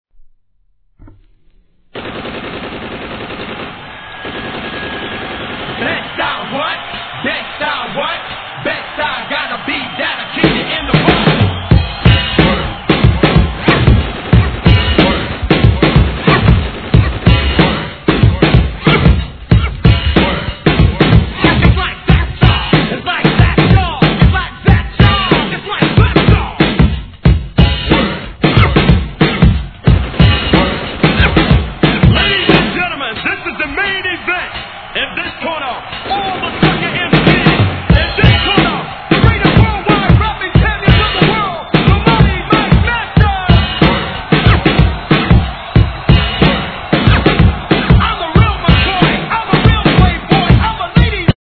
HIP HOP/R&B
定番ブレイクでの'84年オールド・スクール！！